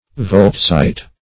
Search Result for " voltzite" : The Collaborative International Dictionary of English v.0.48: Voltzite \Voltz"ite\, n. [So named in honor of Voltz, a French engineer.]